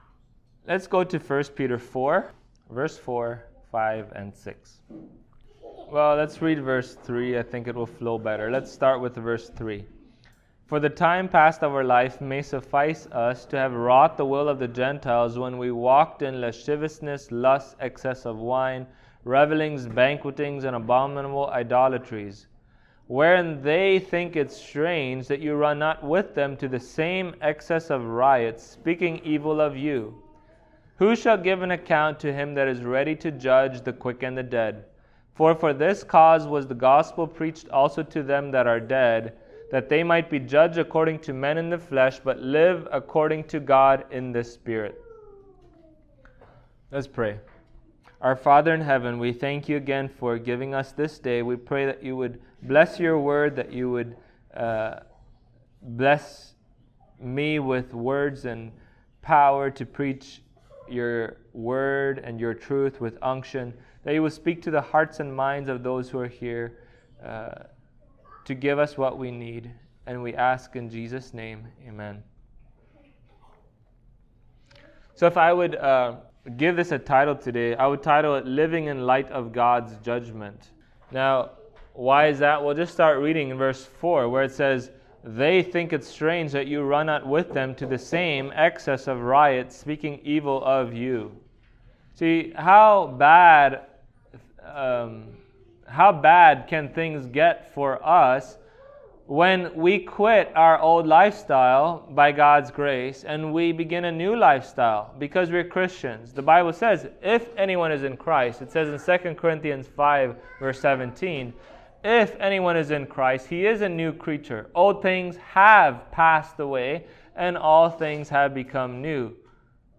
Passage: 1 Peter 4:4-6 Service Type: Sunday Morning